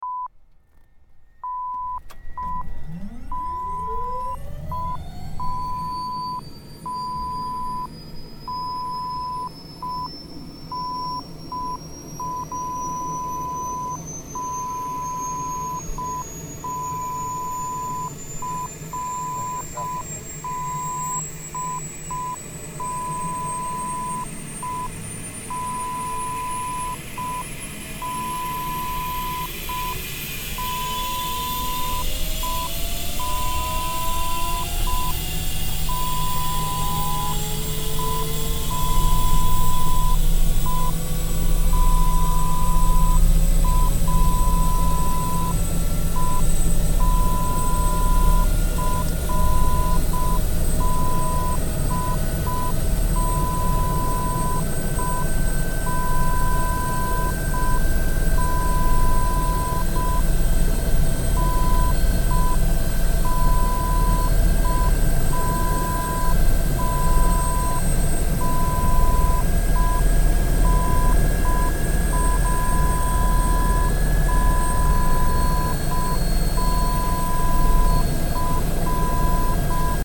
Helicopter Powering up and Take Off Sound Effect SFX
This sound effect is a stereo recording of a helicopter powering up and taking off, shot from an inside perspective.
Previews are low quality mp3 files with security watermark beeps.
HeliPowerUpTakeOffPreview.mp3